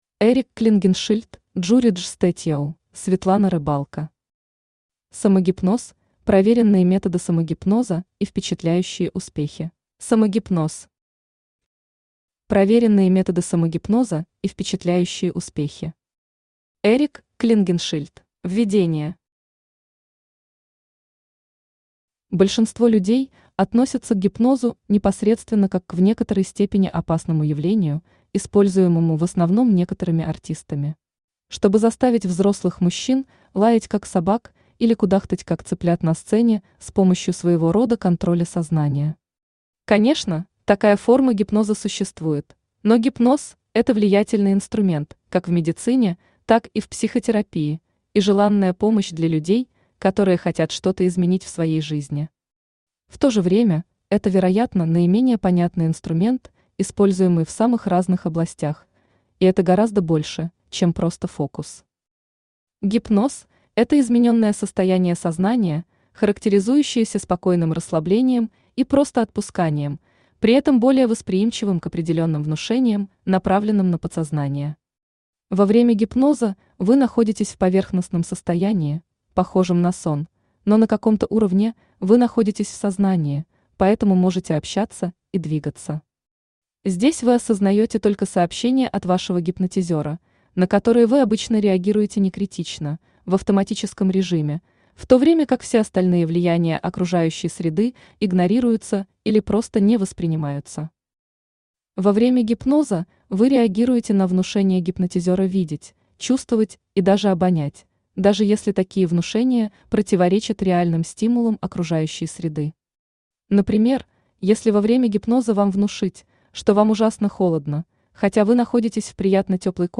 Aудиокнига Самогипноз – проверенные методы самогипноза и впечатляющие успехи Автор Jurij Walerjewitsch Statjow Читает аудиокнигу Авточтец ЛитРес.